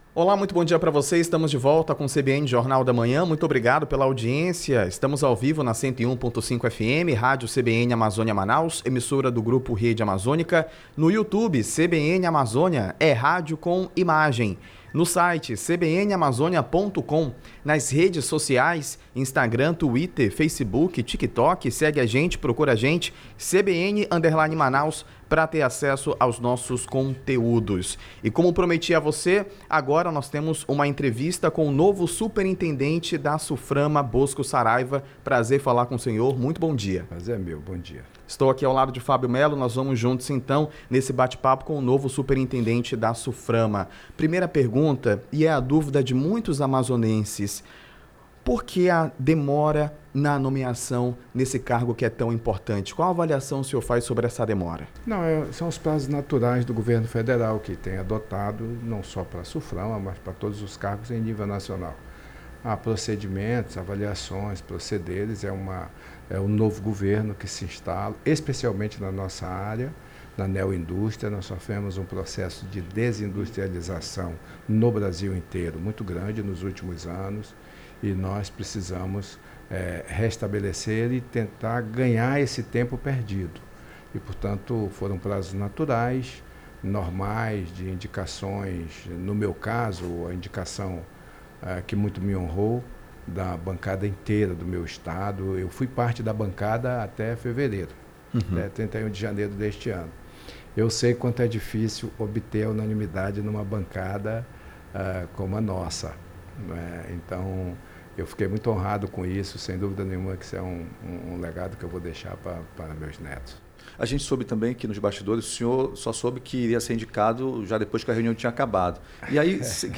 Em entrevista à CBN Amazônia, o novo superintendente da Suframa também falou sobre a relação com o vice-presidente da República e o ministro da Indústria e Desenvolvimento, Geraldo Alckimin; Saraiva justificou a demora na nomeação e pontou a relação com o Governo do Amazonas e Prefeitura de Manaus
8H-ENTREVISTA-BOSCO.mp3